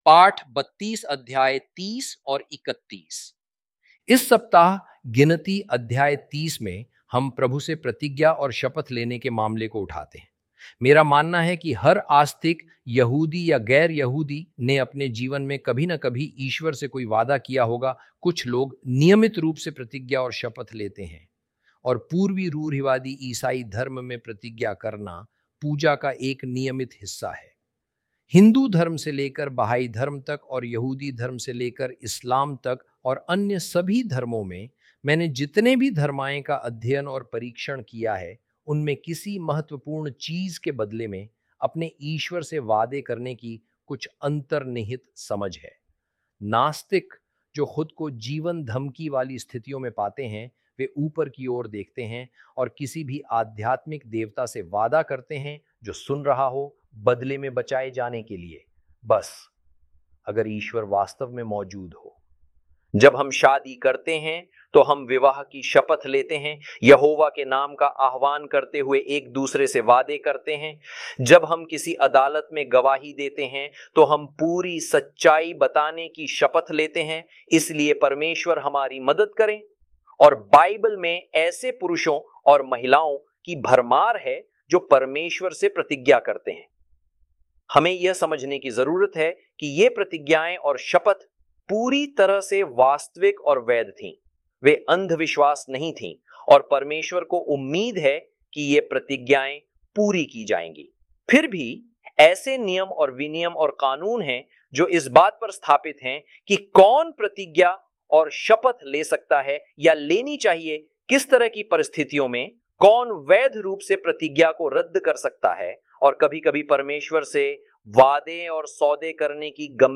Audio Lessons